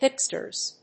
/ˈhɪpstɝz(米国英語), ˈhɪpstɜ:z(英国英語)/